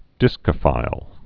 (dĭskə-fīl)